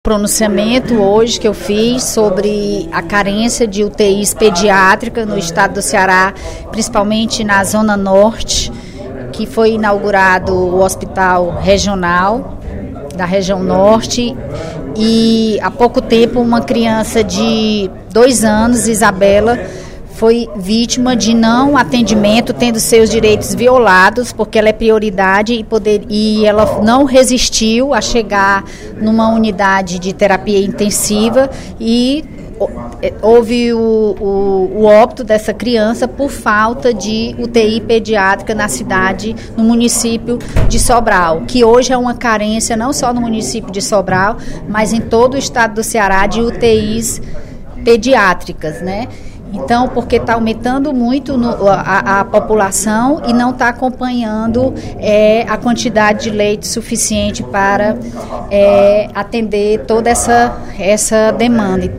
A deputada Bethrose (PRP) compartilhou sua preocupação, durante pronunciamento no primeiro expediente da sessão plenária desta terça-feira (18/12), com o corte de 550 leitos hospitalares no Ceará nos últimos sete anos.